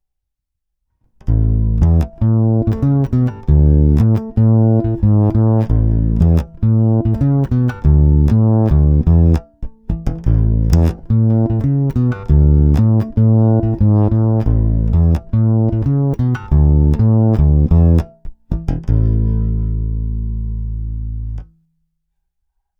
Není-li uvedeno jinak, následující nahrávky byly provedeny rovnou do zvukové karty, korekce basů, středů i výšek byly přidány cca na 1/2, tónová clona vždy plně otevřená. Hráno vždy blízko krku.
Jen piezo snímač, ale korekce basů naplno, středy na 1/2, výšky na nule.